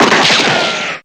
gun2.wav